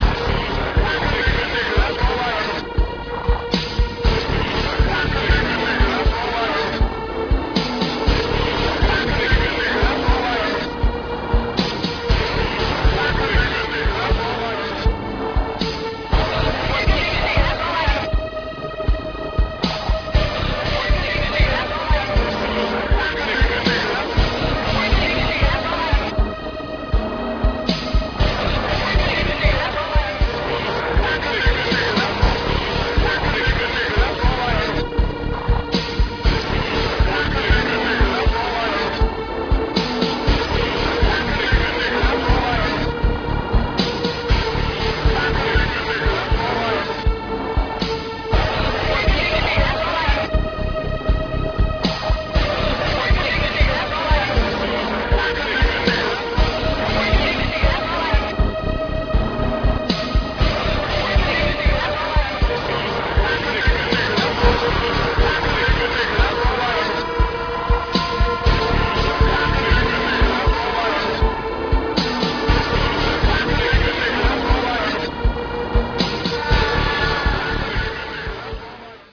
INDUSTRIAL ELECTRÓNICO